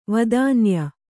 ♪ vadānya